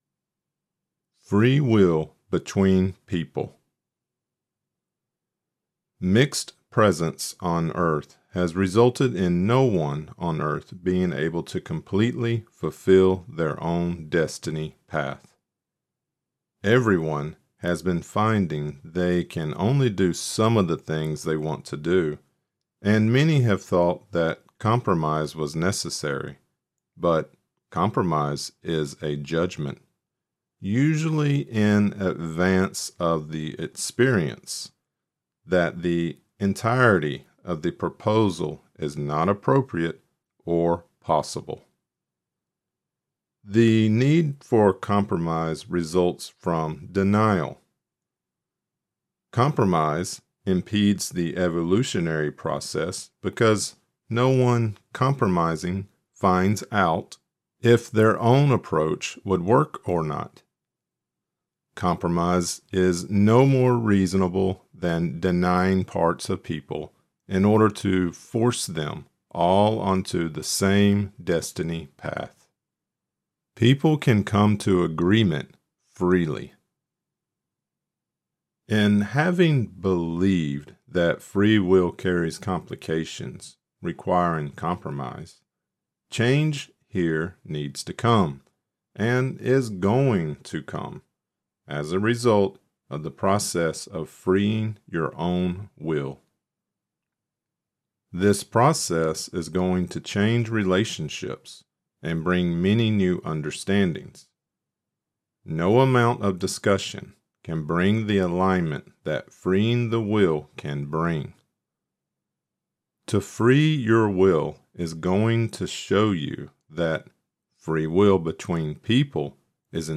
There are five sections in part 14: * Compromise * Denial * Conflict on Earth * The Need of Self * My Loving Light Lecture